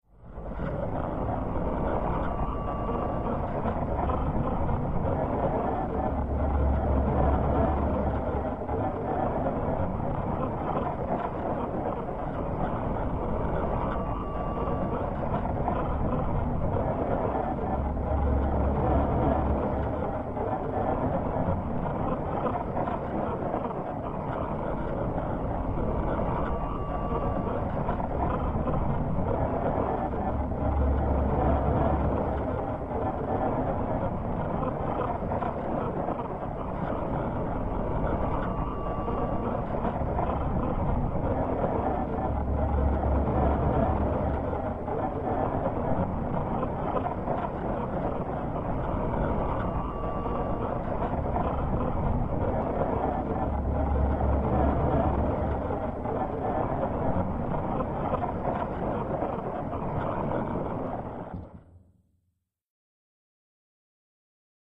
Creature Growl / Vocals Cave Textures Creature, Growl, Vocals, Cave